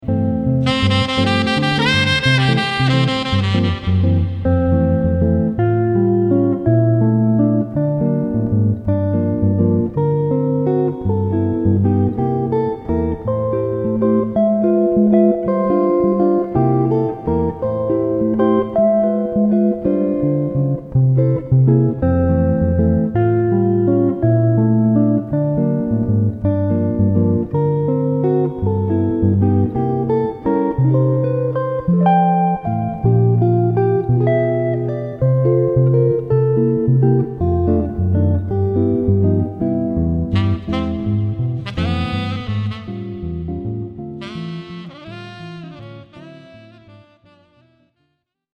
a little chord-melody work